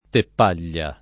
[ tepp # l’l’a ]